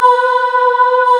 M1 VOICES #2.wav